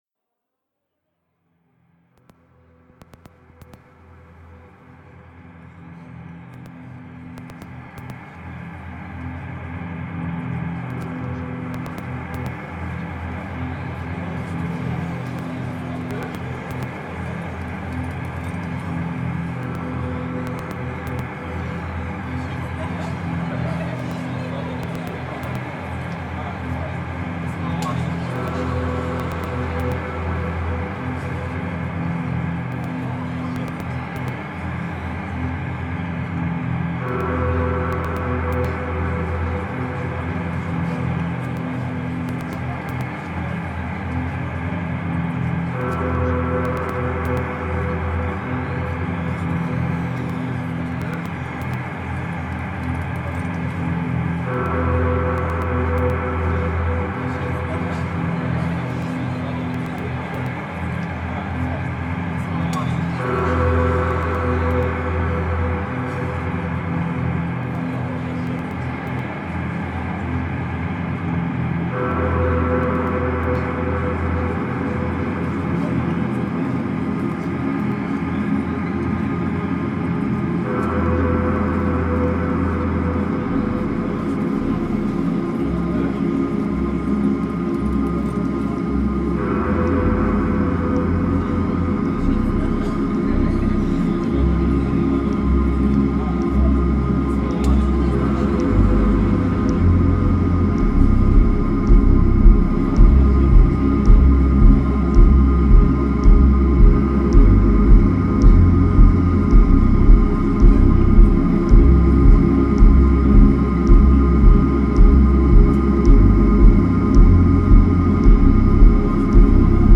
Genre: Dub Techno/Ambient/Drone/Techno.